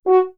015_FH F#4 SC.wav